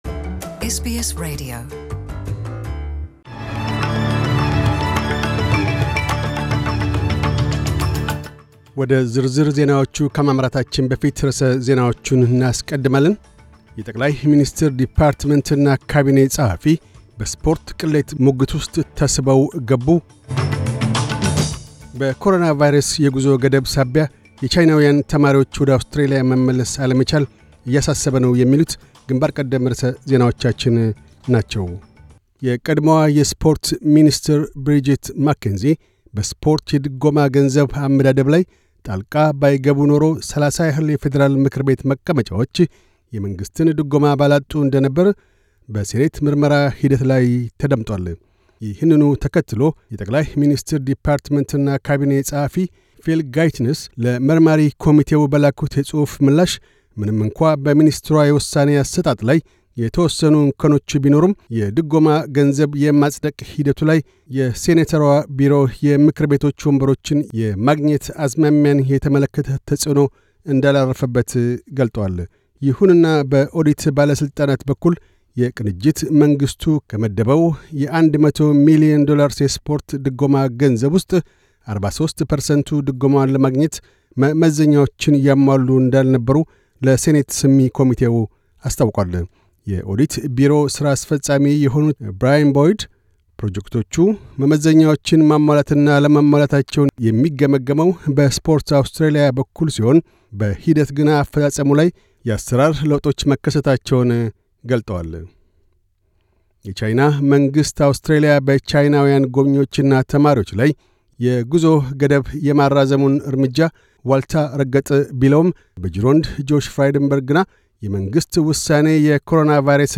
News Bulletin 1402